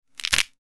Bone Crack Sound Effect
Intense bone cracking sound effect with a sharp break and crunchy texture.
Bone-crack-sound-effect.mp3